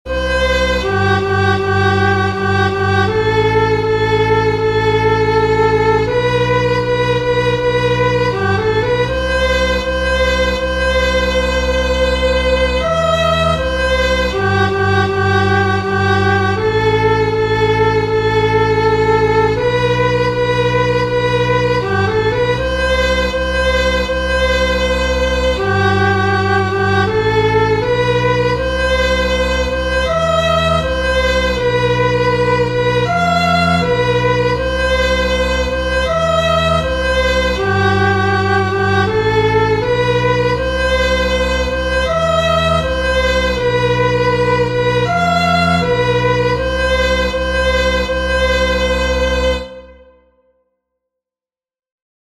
An orchestra of three is playing.
It is a rather brief polka of two musical phrases, with dancing notes, cheerful but very empty. The instruments are slightly out of tune with each other and consequently there is between them, as well as between the successive notes, a strange incoherence and, as it were, empty spaces.